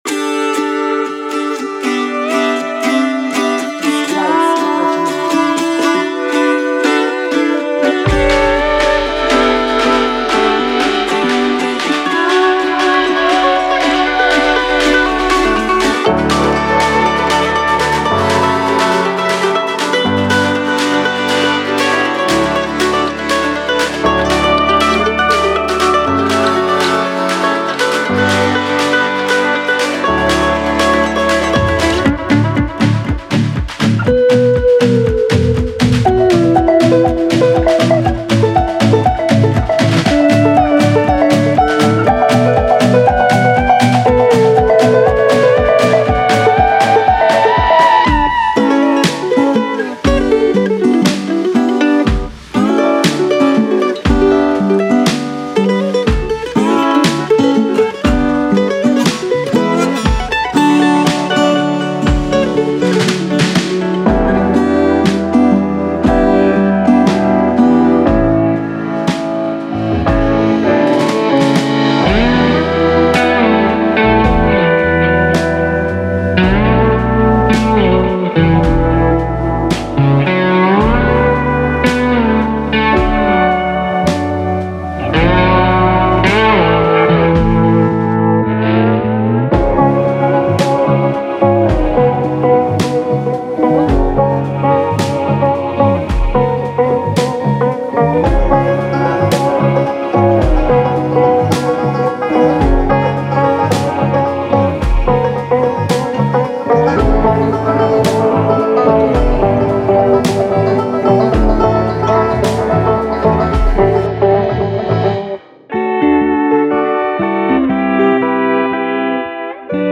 您会发现三角钢琴，踏板钢，扬琴，路德维希鼓，电吉他和原声吉他，口琴等的声音。
-275个音乐循环